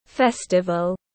Festival /ˈfɛstəvəl/
Festival.mp3